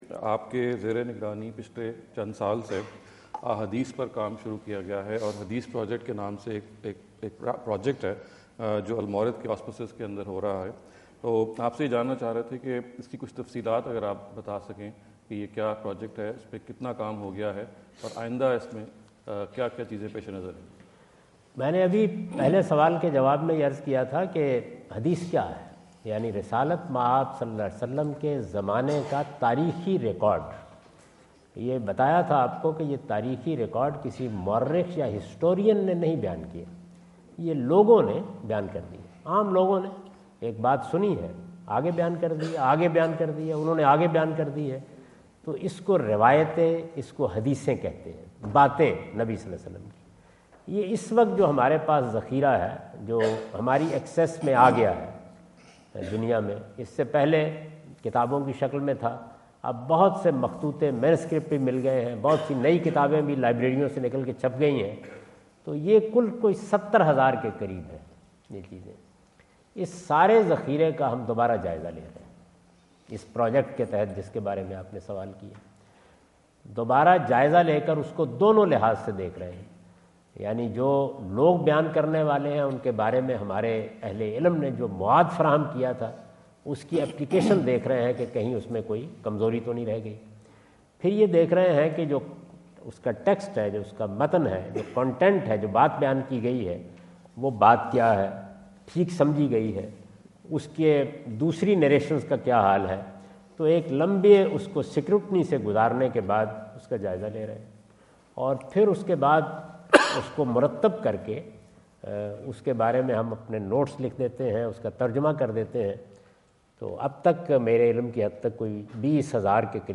Category: English Subtitled / Questions_Answers /
Javed Ahmad Ghamidi answer the question about "Hadith Project of Al-Mawrid" asked at The University of Houston, Houston Texas on November 05,2017.